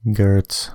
Clifford James Geertz (/ɡɜːrts/
En-ca-geertz.ogg.mp3